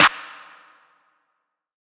SOUTHSIDE_clap_room.wav